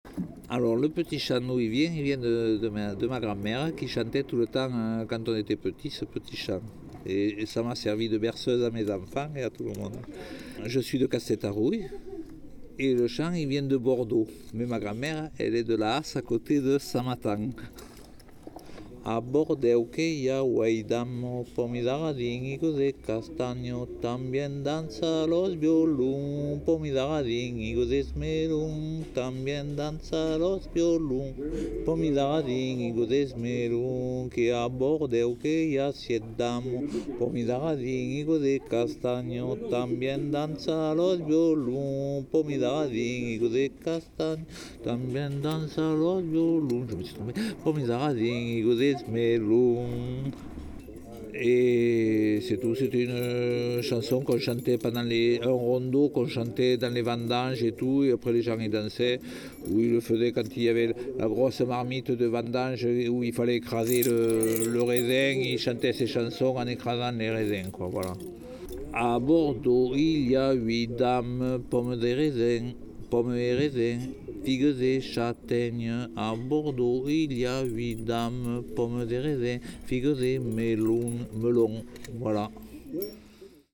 chant en gascon